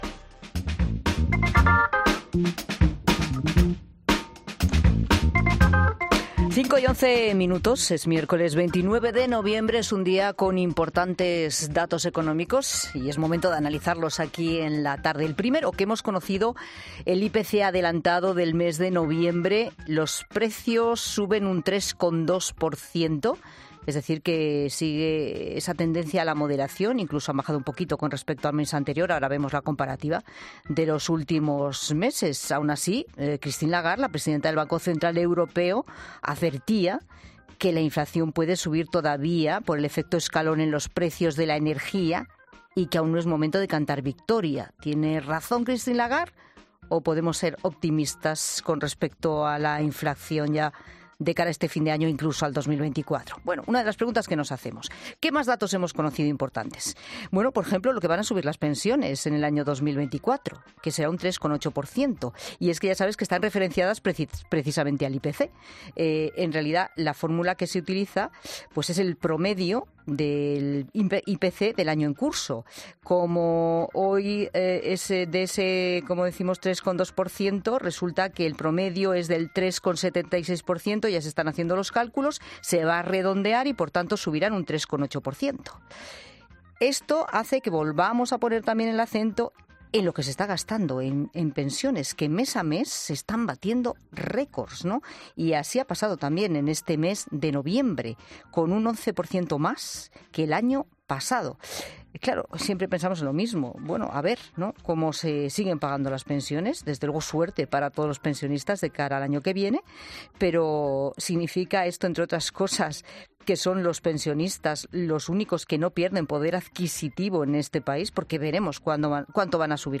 El economista y escritor, Fernando Trías de Bes, ha analizado en La Tarde como afectarán las pensiones a nuestros bolsillos